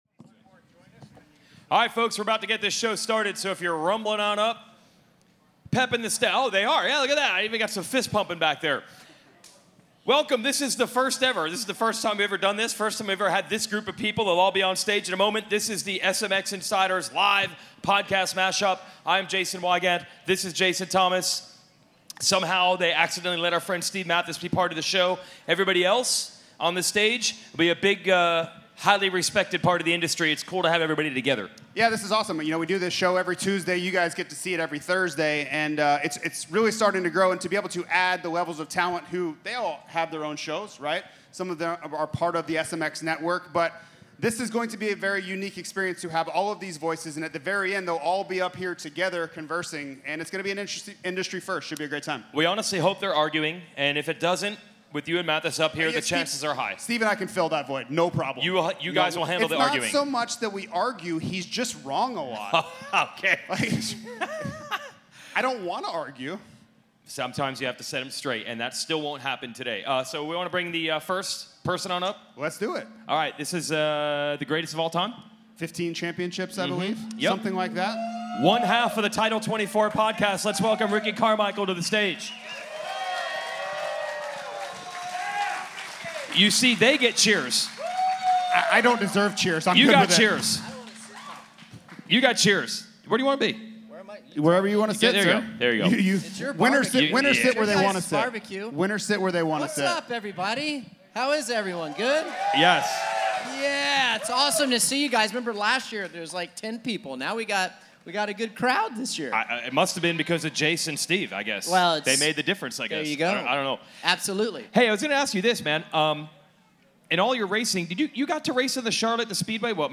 Check out the pre-race live podcast mashup from the first SMX Playoff round in North Carolina.